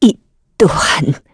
Isolet-Vox_Dead_kr_b.wav